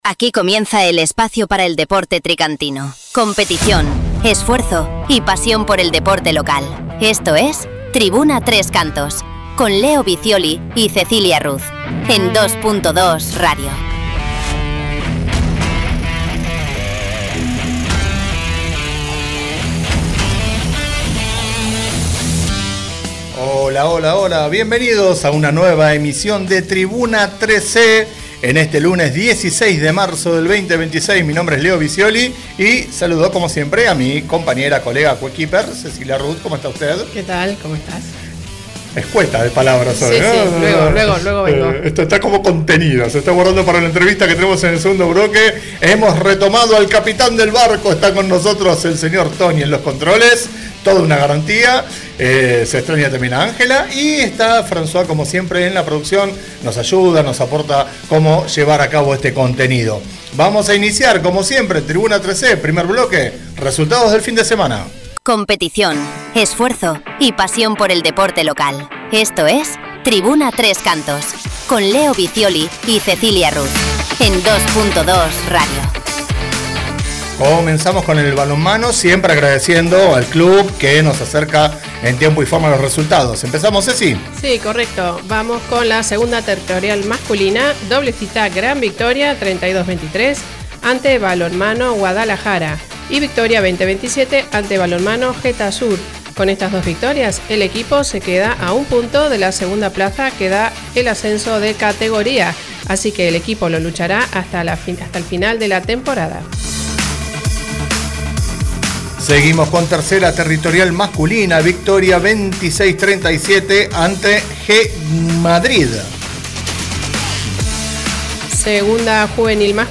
entrevistamos